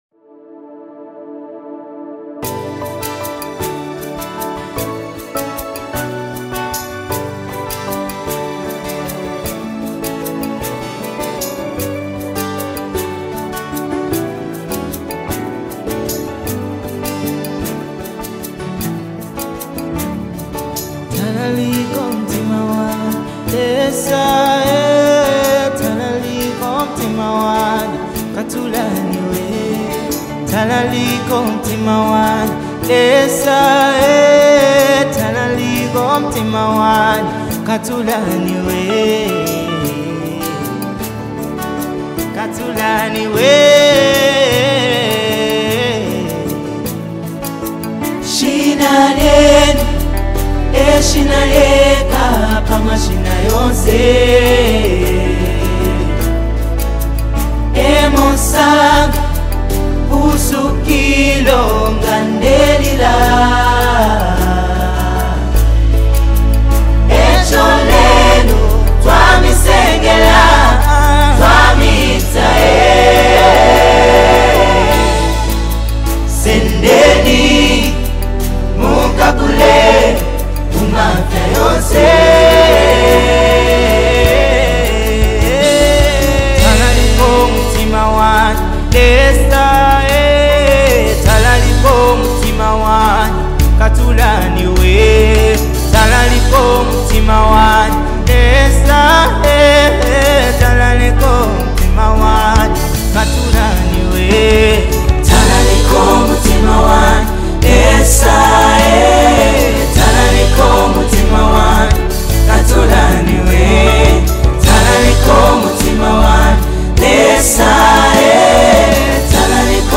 Dancehall styles